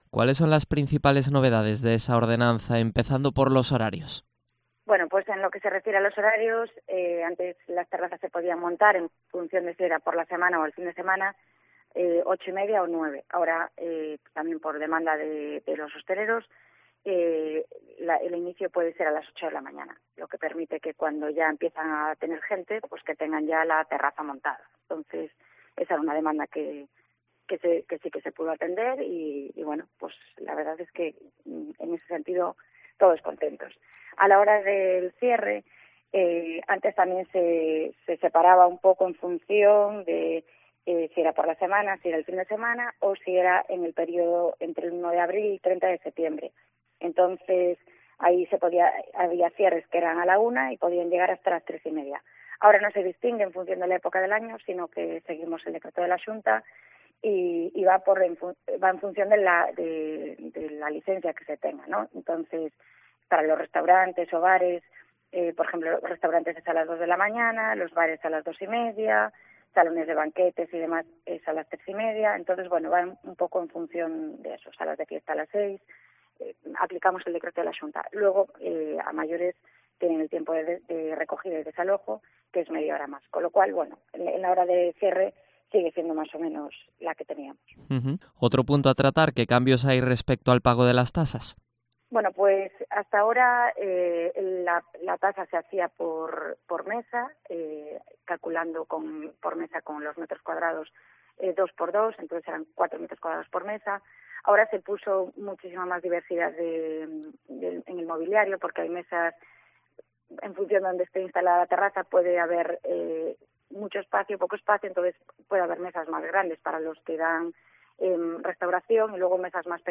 Entrevista a Marián Sanmartín, edil de Medio Ambiente de Marín
AUDIO: Entrevista a Marián Sanmartín, edil de Medio Ambiente de Marín